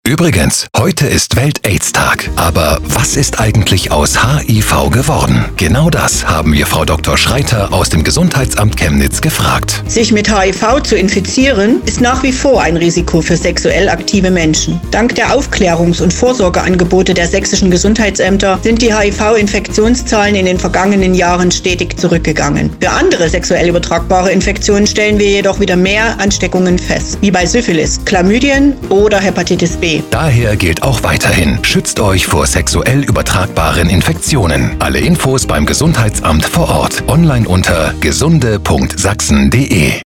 OEGD_Radiospot_sexuelle_Gesundheit.mp3